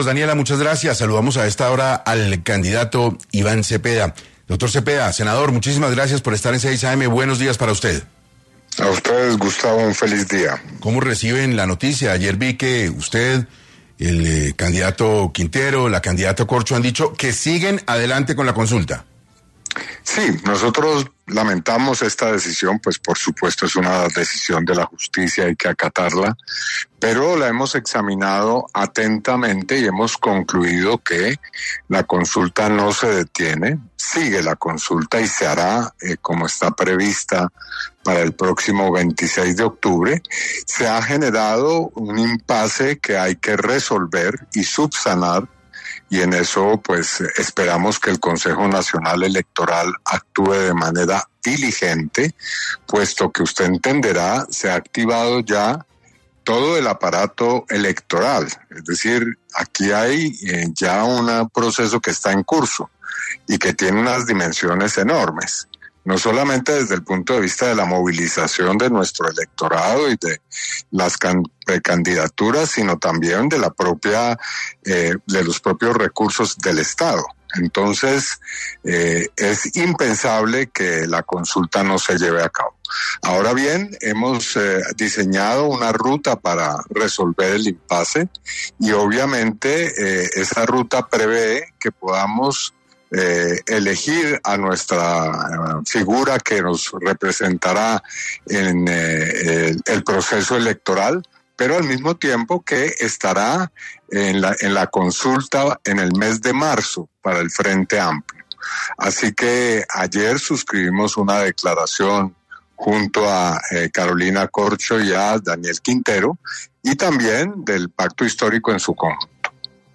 En entrevista con 6AM de Caracol Radio, Cepeda explicó que la decisión judicial conocida en las últimas horas “no detiene el proceso” y que se trata de un impase jurídico que “puede subsanarse con prontitud”.